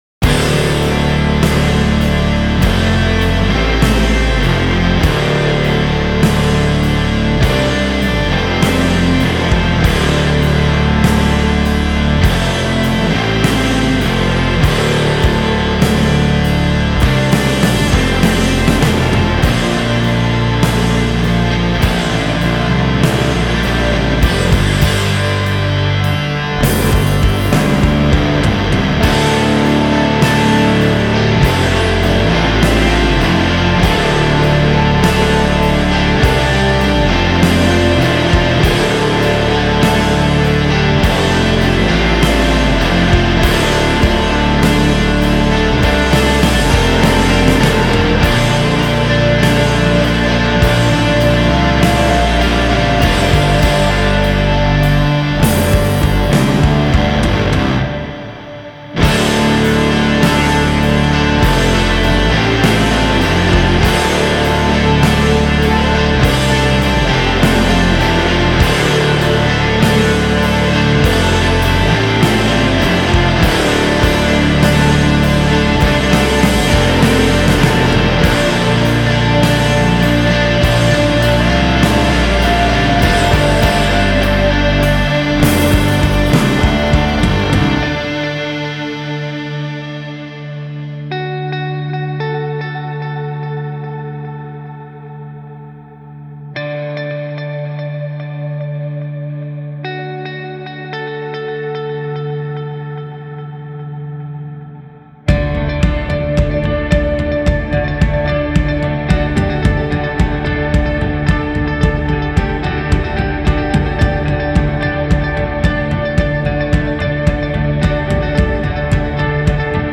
genre: Post-Rock